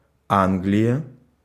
Ääntäminen
Ääntäminen Tuntematon aksentti: IPA: /ˈɛŋland/ Haettu sana löytyi näillä lähdekielillä: ruotsi Käännös Ääninäyte Substantiivit 1.